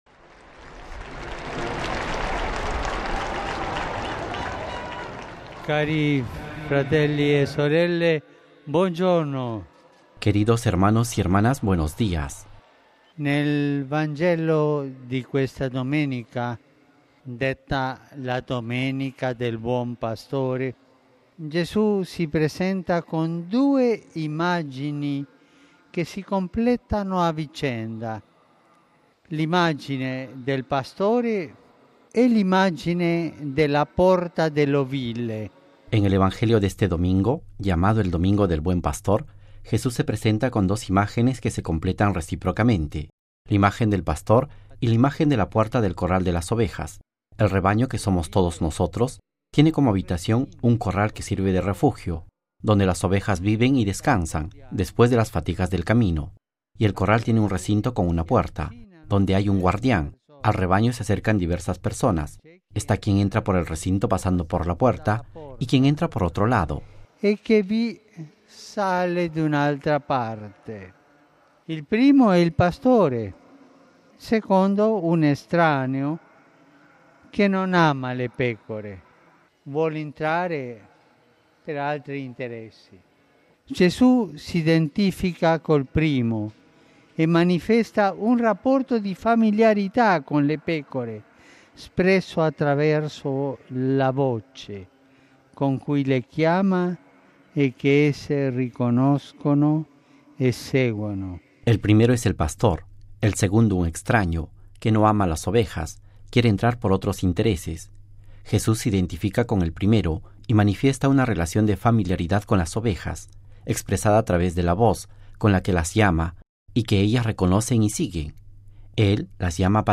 Texto y audio del Santo Padre Francisco antes de rezar a la Madre de Dios: